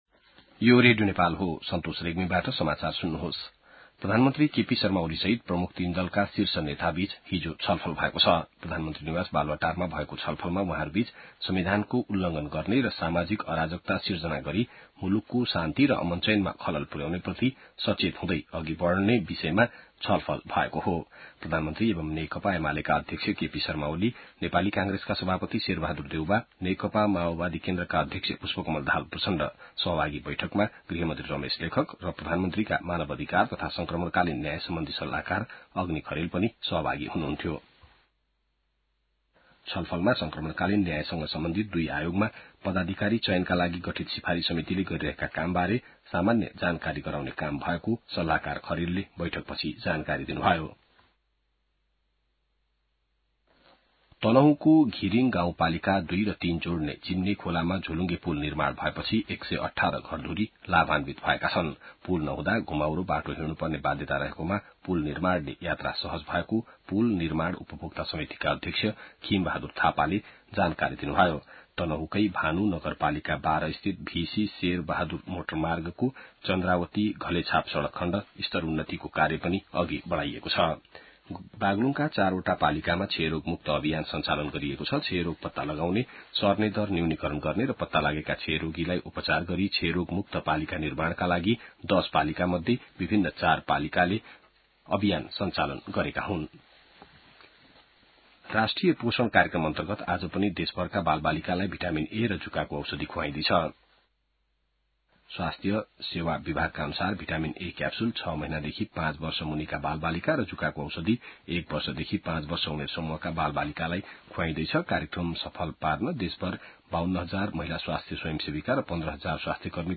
बिहान ६ बजेको नेपाली समाचार : ७ वैशाख , २०८२